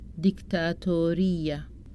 Sudanese Arabic Vocabulary List